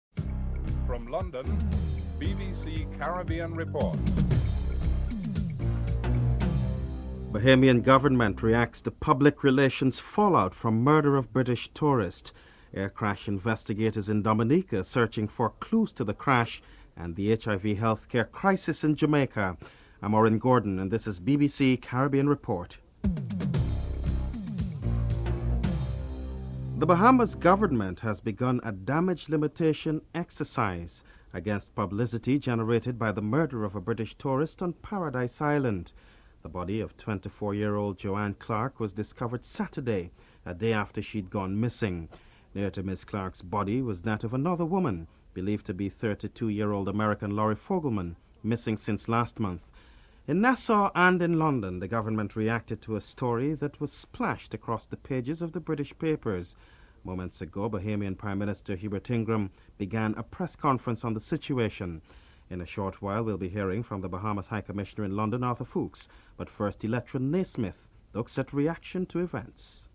2. Bahamian government reacts to public relations fall out for murder of British tourist. Bahamas High Commissioner Arthur Foulkes is interviewed (00:27-05:41)